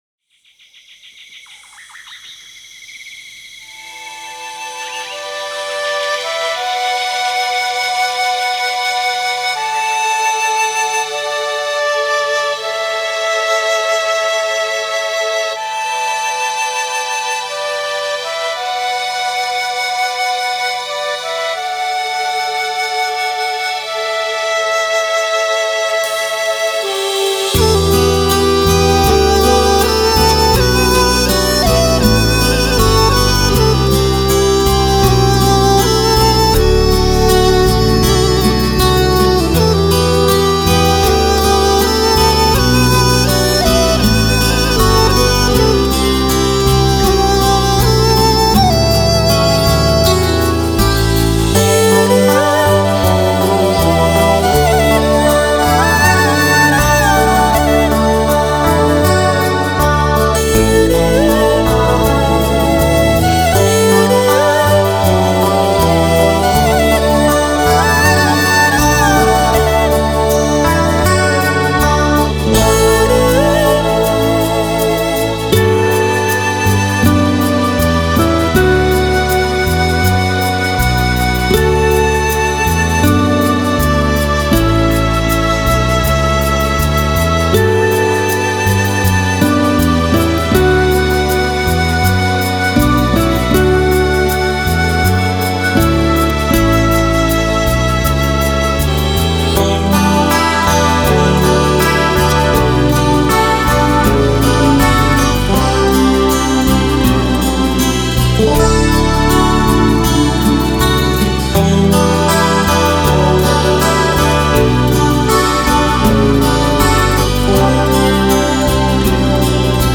歌曲风格：轻音乐 (Easy Listening) / 纯音乐 (Pure Music)